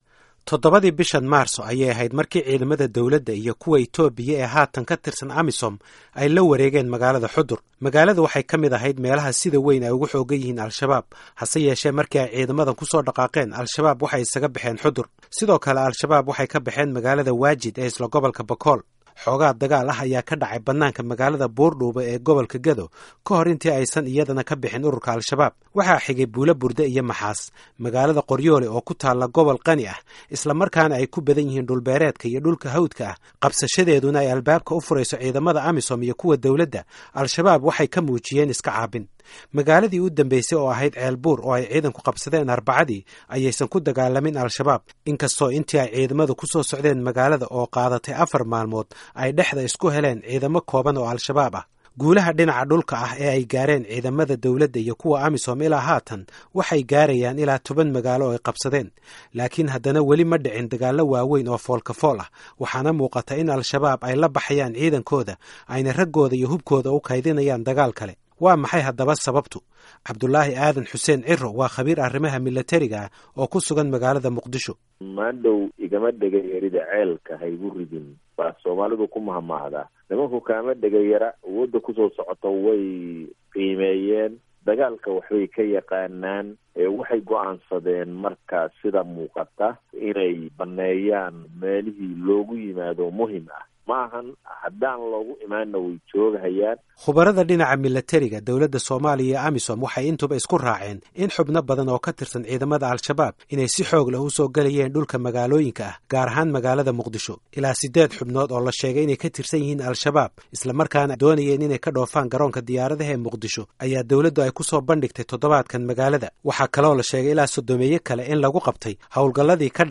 Falanqayn: Gulufka ka dhanka ah al Shabaab
Warbixin ku saabsan al Shabab iyo hawlgallada ka dhanka ah